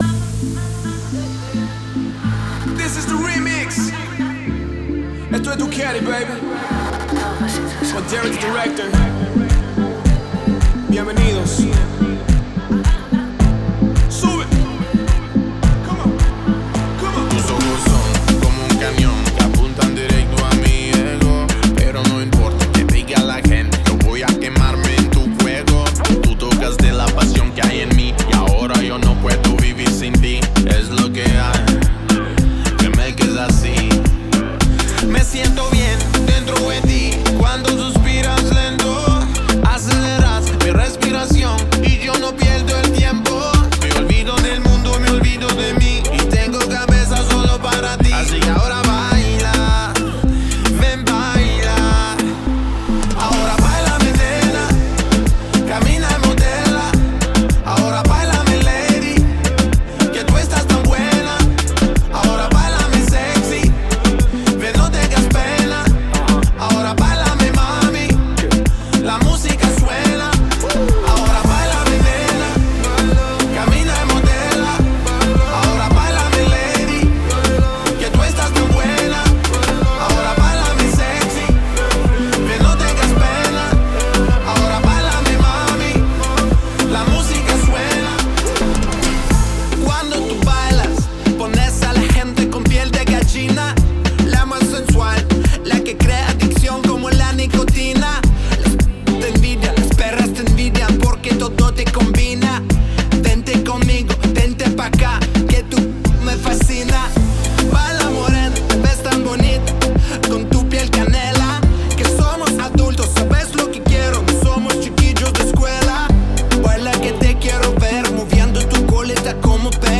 это зажигательный трек в жанре поп с элементами EDM